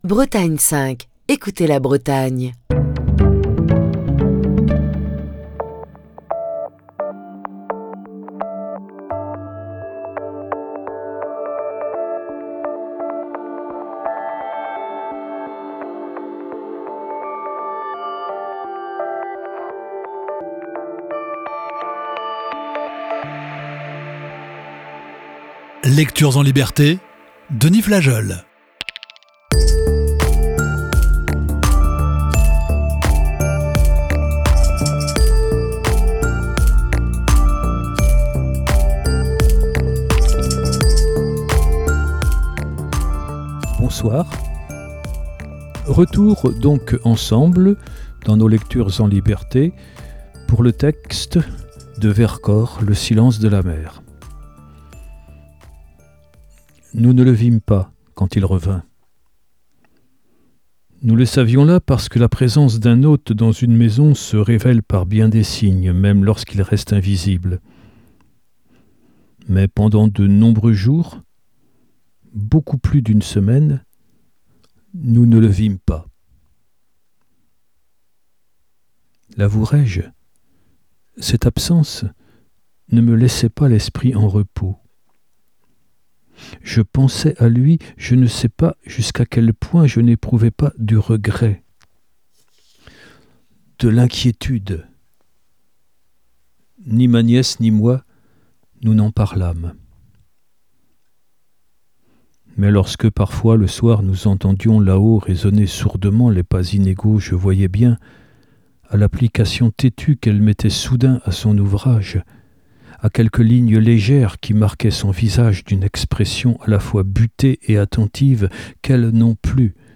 Lecture(s) en liberté